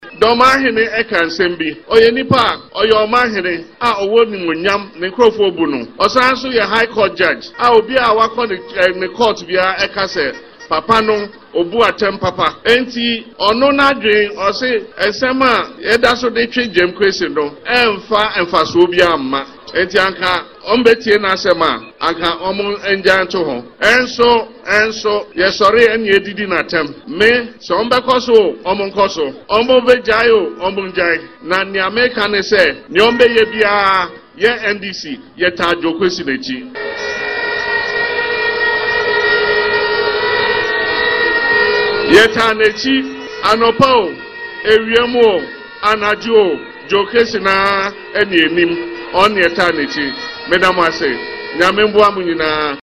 Speaking during the ‘Thank You Tour’ following the victory of James Gyakye Quayson in the by-election on June 27, Mahama acknowledged the significance of Assin North in Ghana’s history and expressed confidence in the NDC’s chances of recapturing power in the upcoming elections.